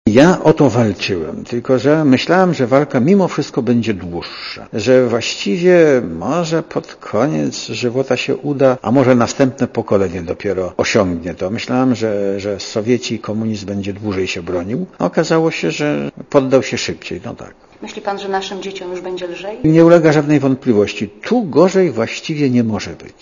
Źródło zdjęć: © RadioZet 30.04.2004 | aktual.: 30.04.2004 19:47 ZAPISZ UDOSTĘPNIJ SKOMENTUJ Dla Radia ZET mówi Lech Wałęsa Uroczystość odbędzie się 3 maja w Strasburgu.